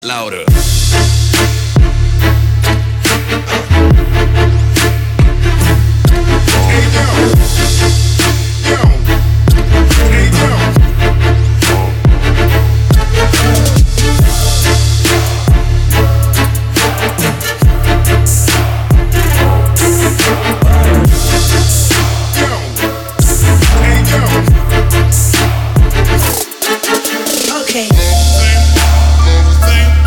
• Качество: 320, Stereo
Trap
low bass
низкий бас